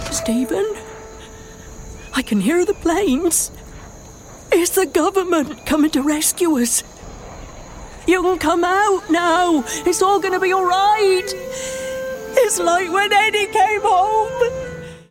Bristol ● West Country Adult
Audio Drama ● Videogame